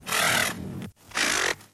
Small Dino Raspy Calls
The call of a small dinosaur with a raspy voice. (Synthesized from some CC0 horse snorts.)
small_dino_raspy_calls.mp3